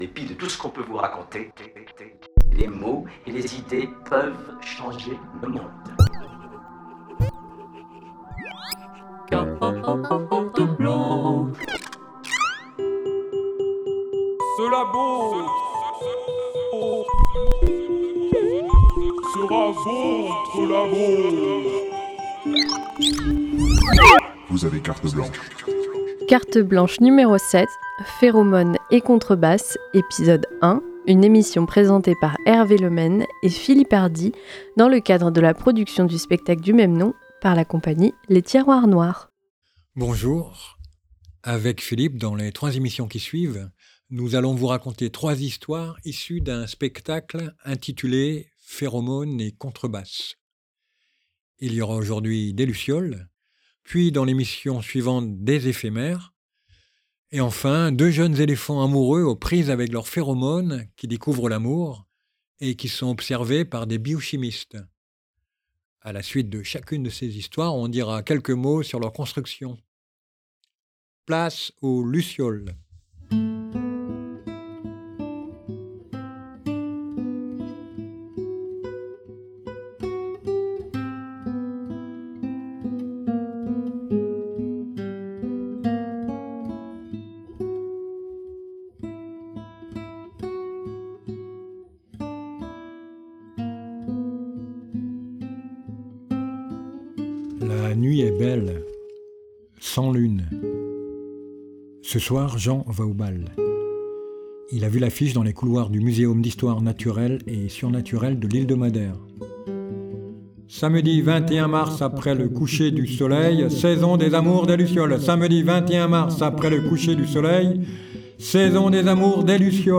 La Compagnie de théâtre Les Tiroirs Noirs propose une version radiophonique de son dernier spectacle, suivie d’une discussion sur sa construction. Dans ce premier épisode, une luciole mâle part à la recherche de l’amour et manque de se faire dévorer.